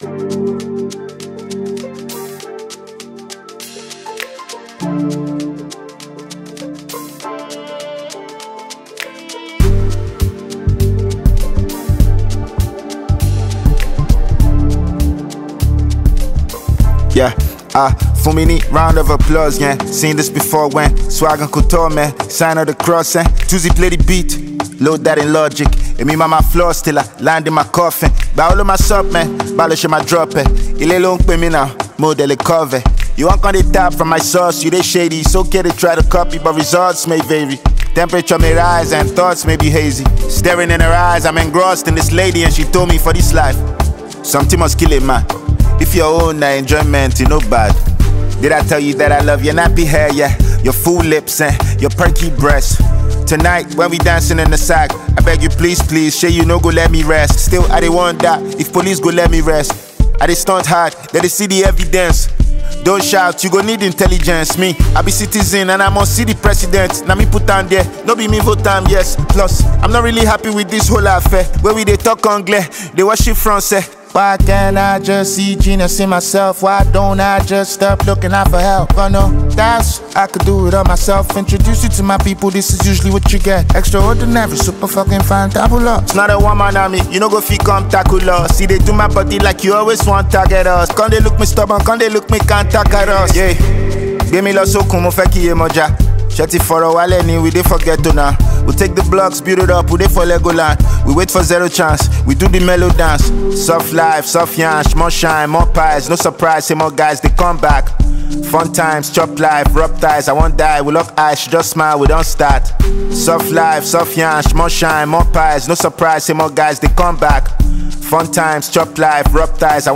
Talented Nigerian rapper, singer and songwriter